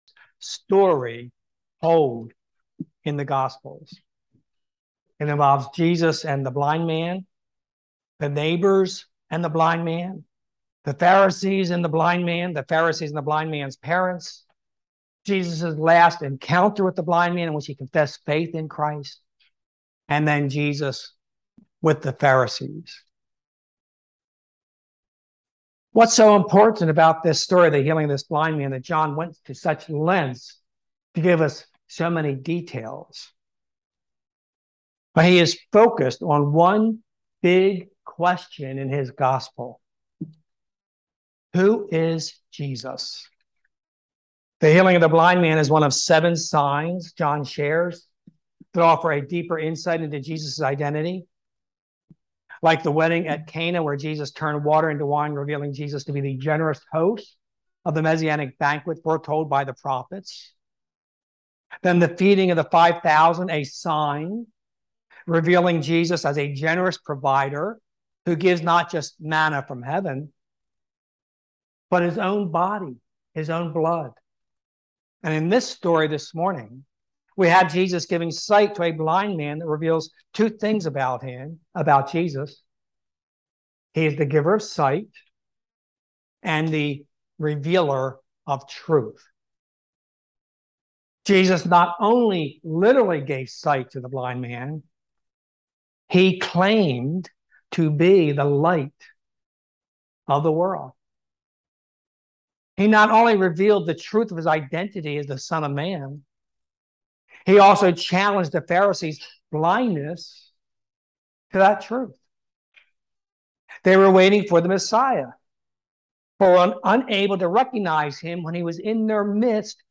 Psalm 23:1 Service Type: Sunday Service He said